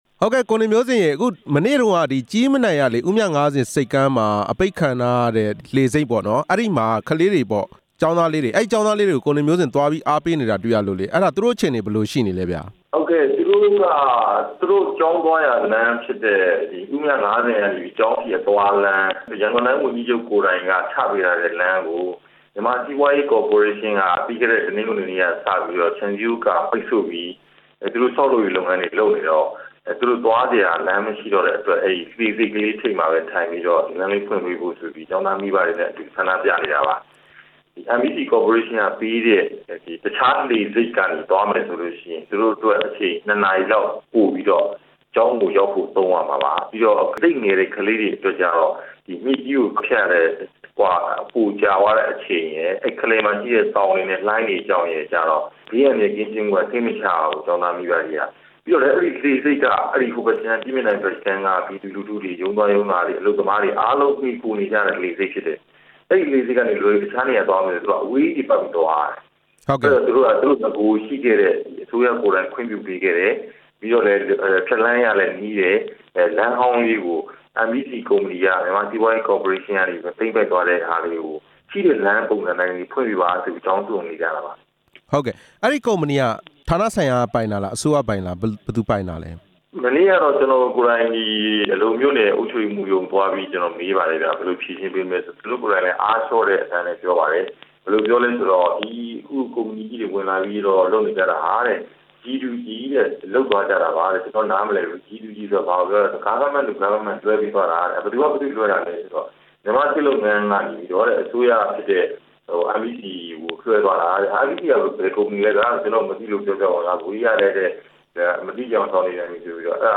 ကူးတို့ဆိပ်ကမ်းကို တပ်မတော် ပိုင် MEC ကုမ္ပဏီက ပိတ်လိုက် တဲ့အကြောင်း မေးမြန်းချက်